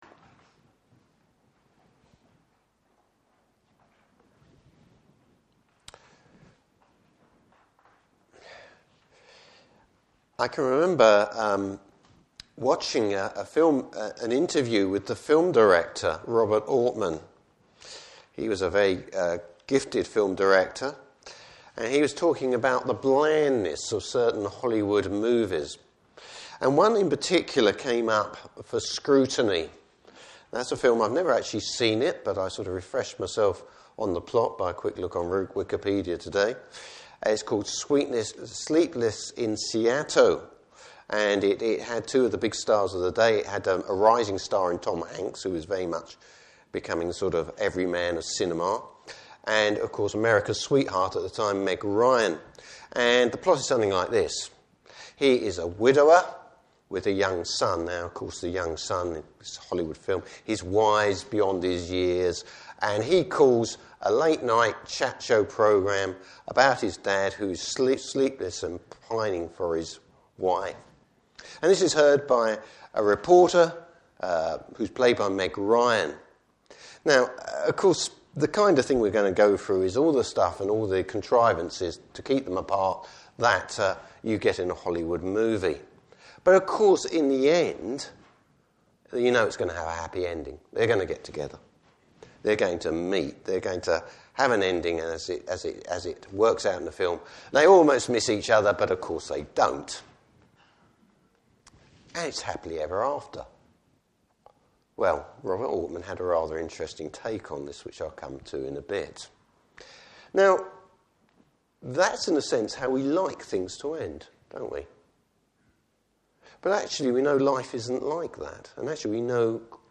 Service Type: Morning Service Bible Text: Nehemiah 12:27-13:30.